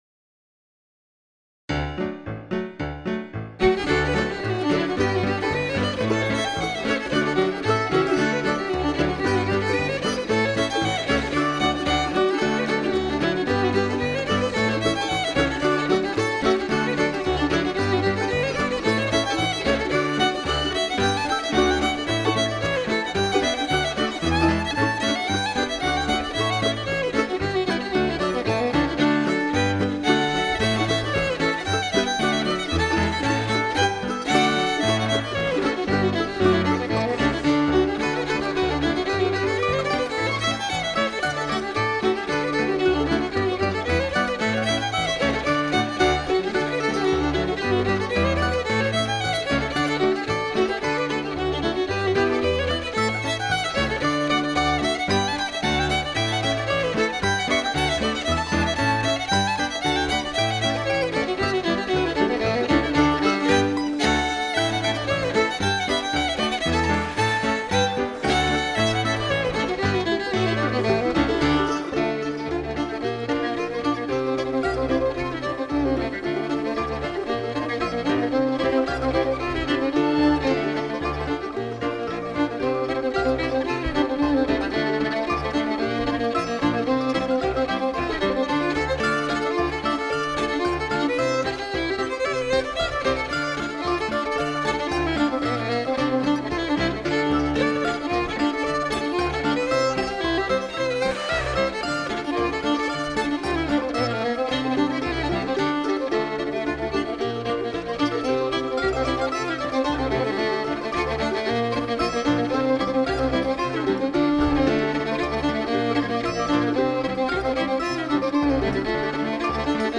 Portfolio of a Fiddle Player
Description: The Gnutones, live at Swing 'N Tern New Year's Eve Contra Dance party, 2002.